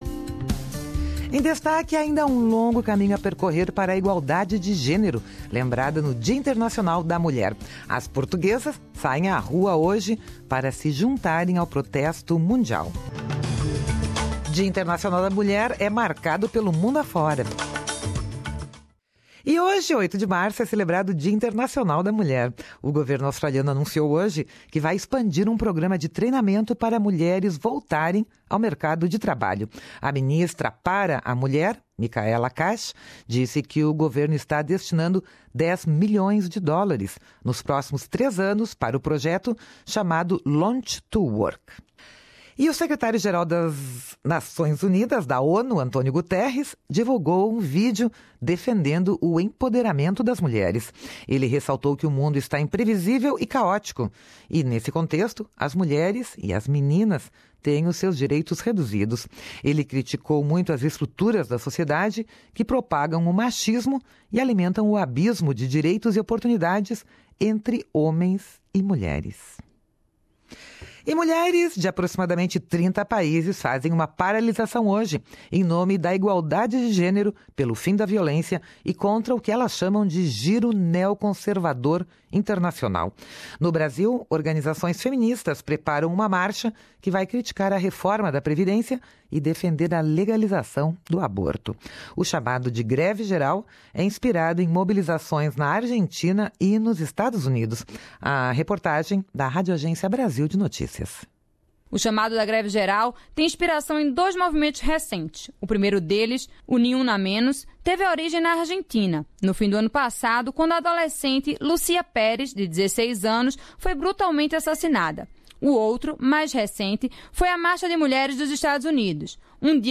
apresenta uma reportagem especial sobre o Dia da Mulher em Portugal e no mundo.